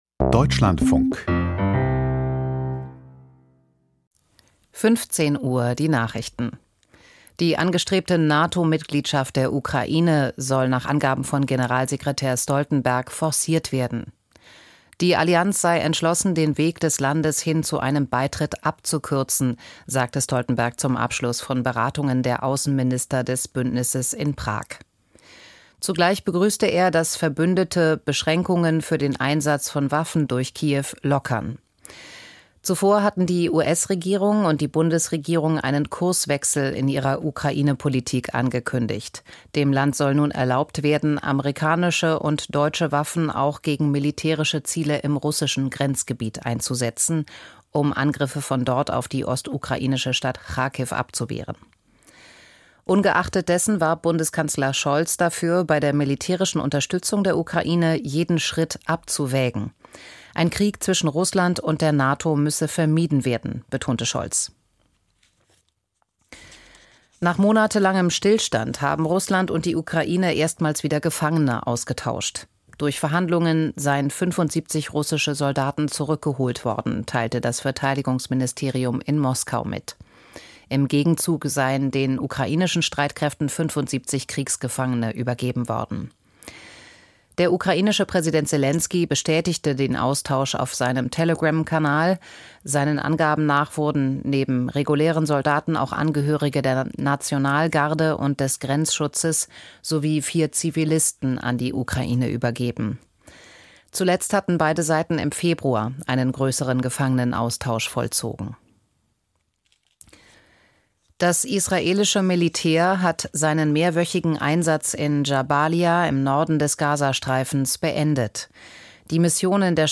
Bildungsprotest 2024: Raus aus der Krise?! Int. Helmut Holter, Thüringen - 31.05.2024